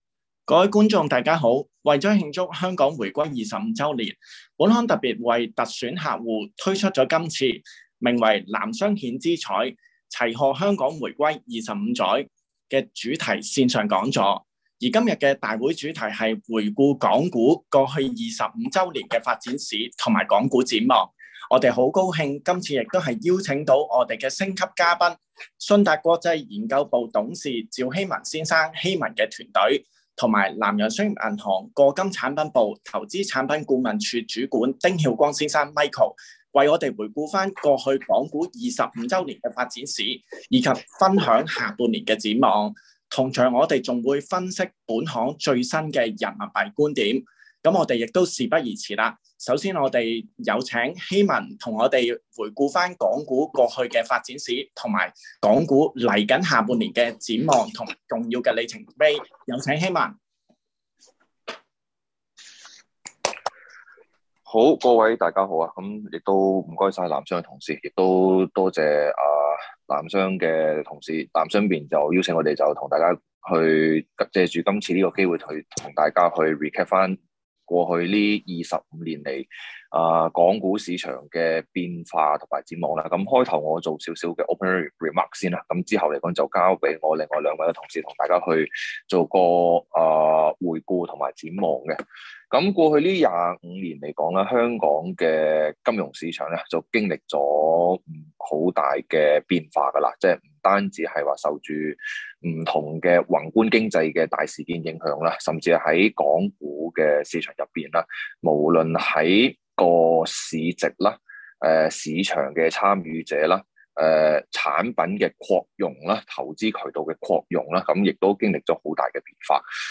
《回顧港股25年發展史及港股下半年展望》講座 (Available in Chinese audio only)